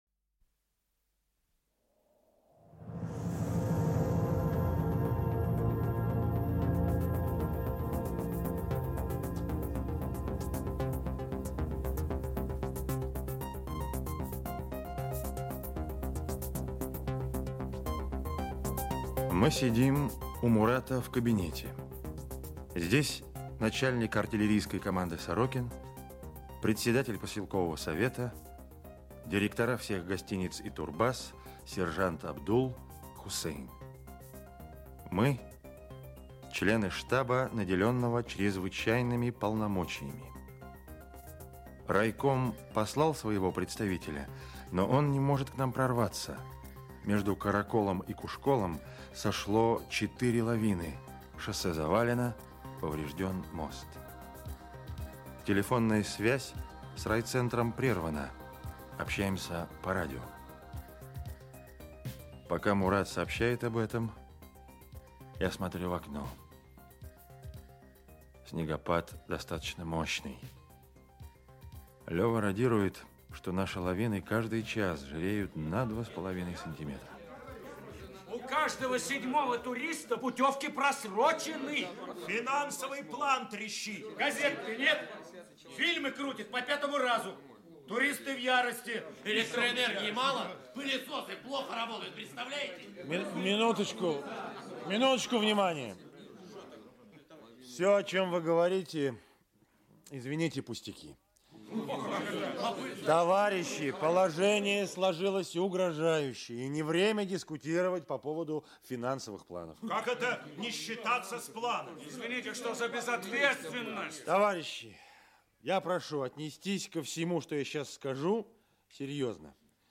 Часть 2 Автор Владимир Санин Читает аудиокнигу Евгений Киндинов.